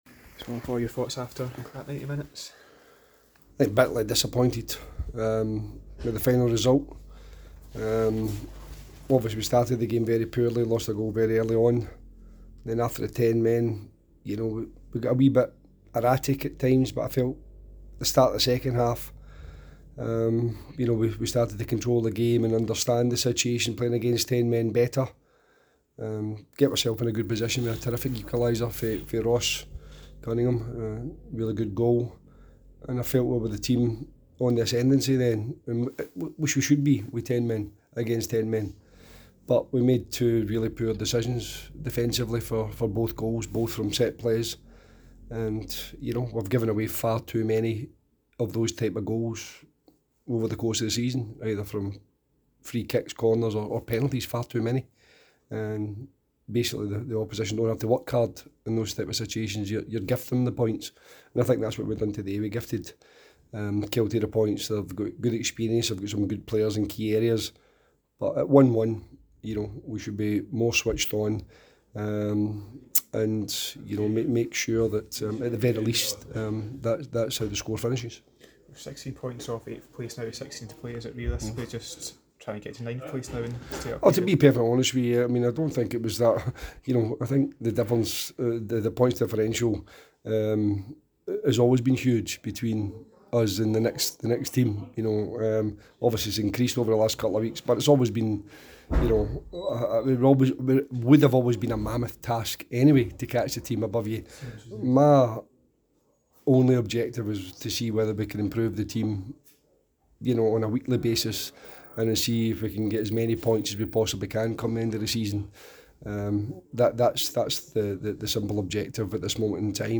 post-match comments following the cinch League 1 fixture